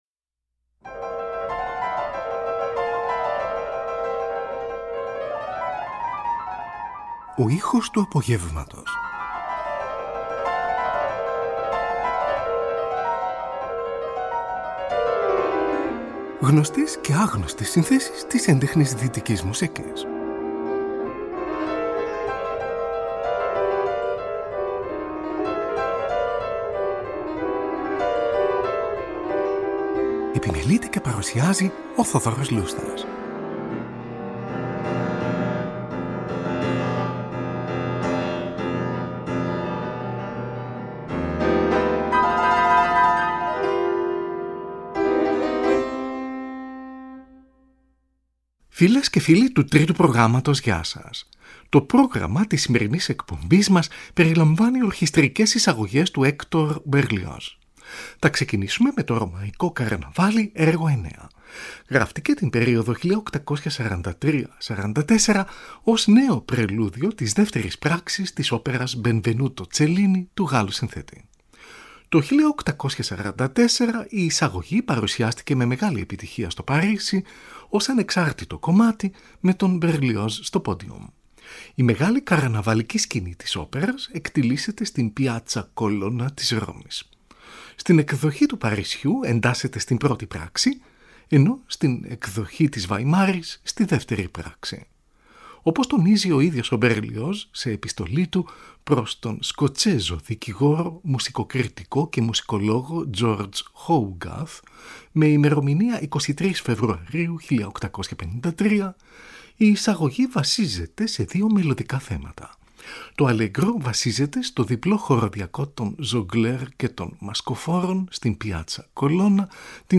Ορχηστρικές Εισαγωγές του Hector Berlioz | Τετάρτη 30 Οκτωβρίου 2024
Ερμηνεύει η Φιλαρμονική του Λονδίνου υπό τον Adrian Boult, καθώς και η Βασιλική Φιλαρμονική του Λονδίνου υπό τον Thomas Beecham.